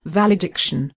Val-e-dic-tion
Click here to hear how it is pronounced